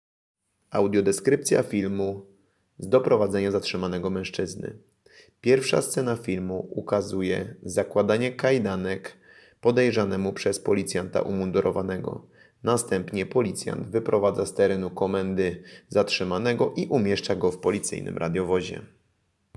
Nagranie audio Audiodeskrypcja_filmu.mp3